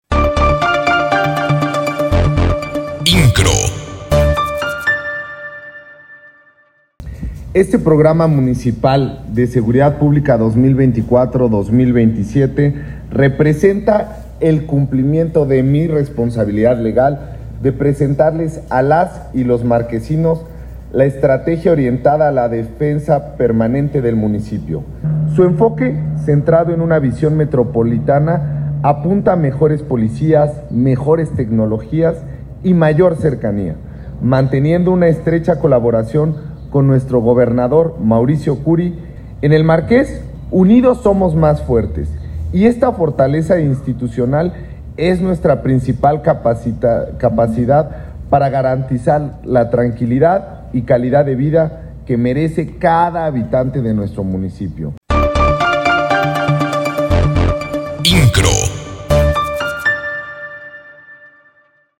Durante su mensaje dirigido a la ciudadanía, Monsalvo Castelán puntualizó que el principal enfoque de dicho programa es garantizar la seguridad de las familias que habitan en el municipio.